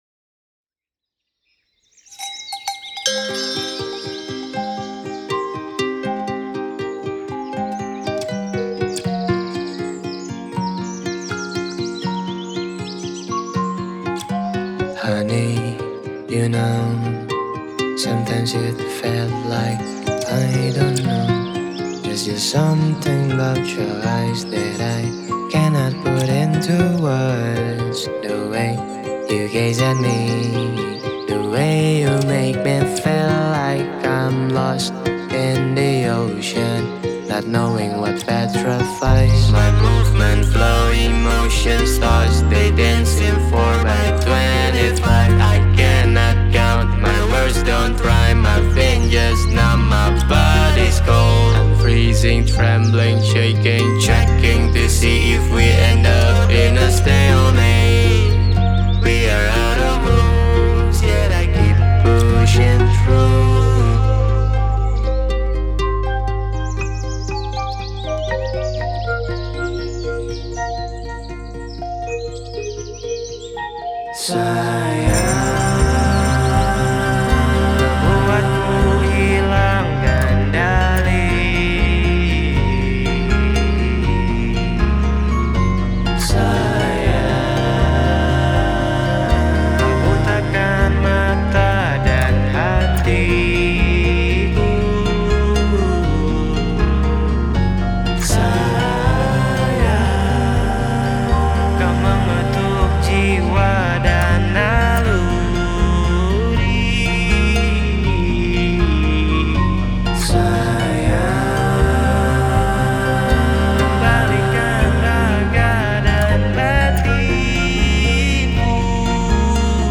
Pop • Palembang